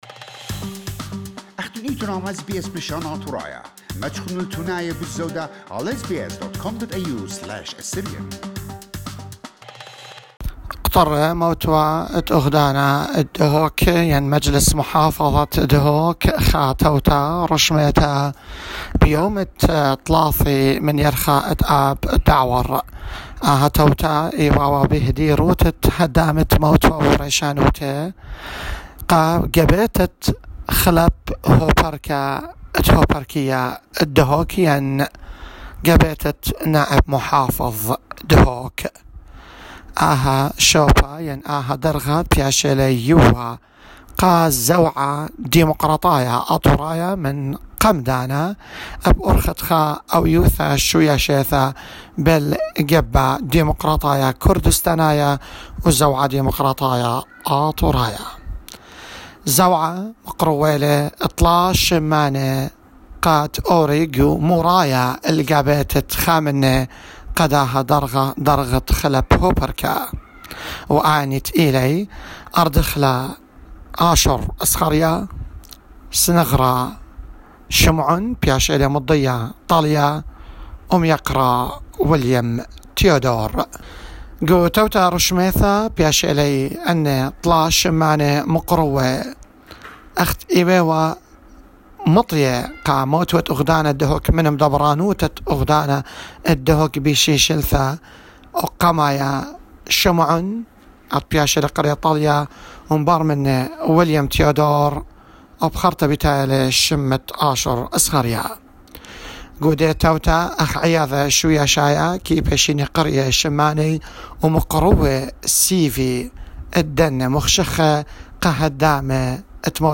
as the council of ministers in Kurdistan region will study the choice and issue an appointing decision. more details in this audio report Source